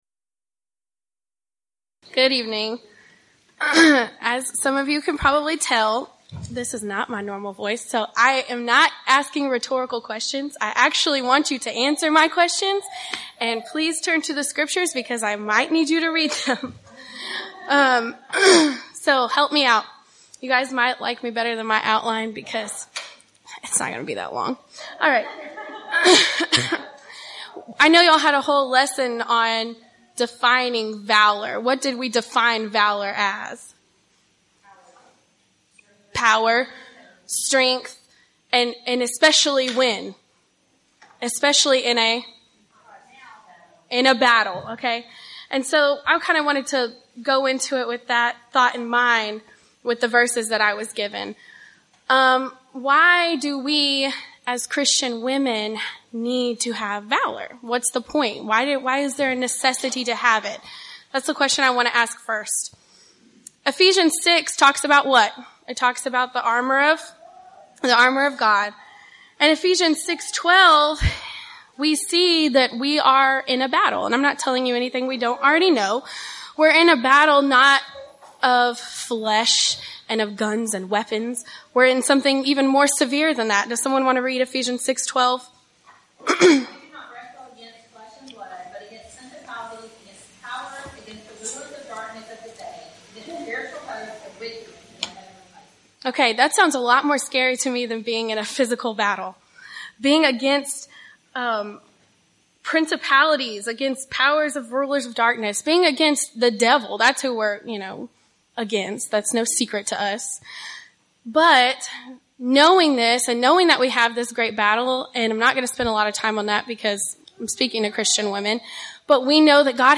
Title: Devotional: Romans 1:16 & 1 Corinthians 1:18
Event: 1st Annual Women of Valor Retreat
Ladies Sessions